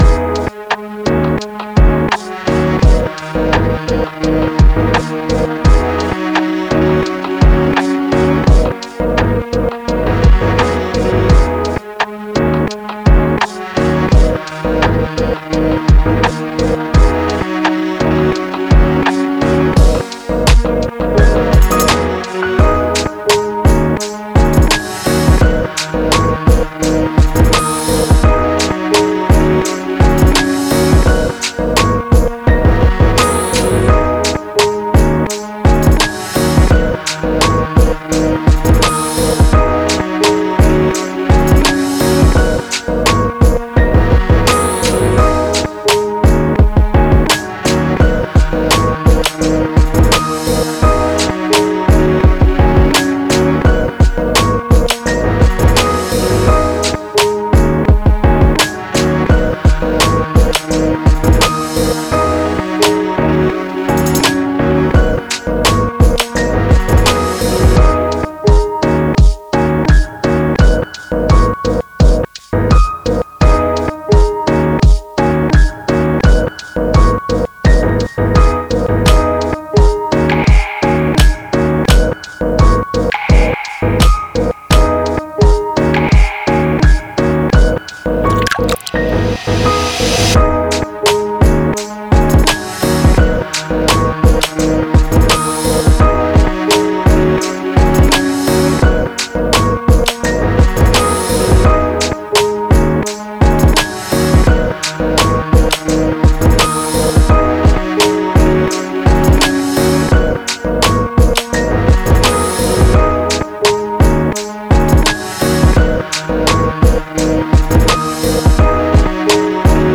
おしゃれ FREE BGM